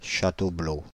Châteaubleau (French pronunciation: [ʃatoblo]
Fr-Châteaubleau.ogg.mp3